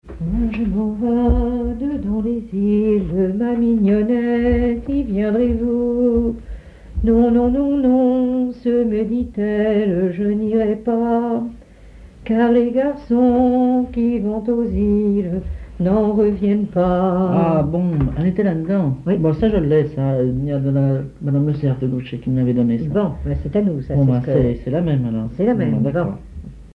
Sainte-Hélène-Bondeville
Genre strophique
Pièce musicale inédite